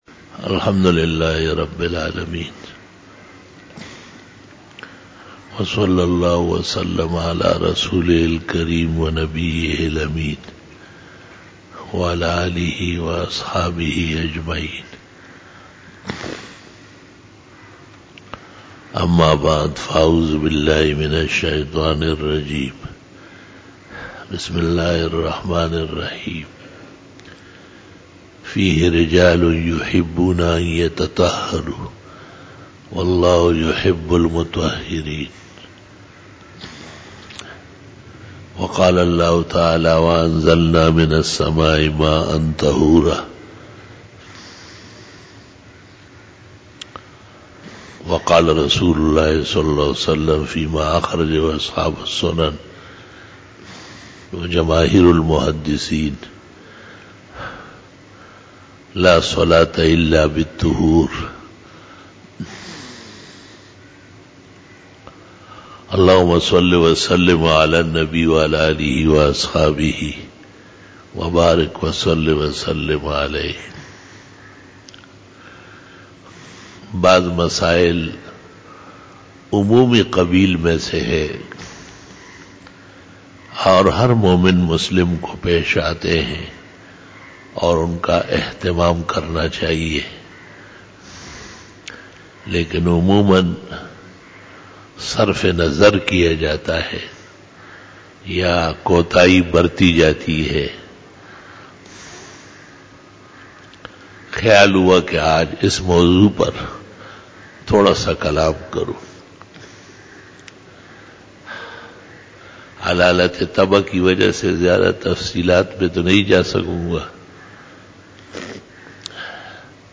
06 BAYAN E JUMA TUL MUBARAK 10 FEBRUARY 2017 (12 Jamadi ul Awwal 1438H)
Khitab-e-Jummah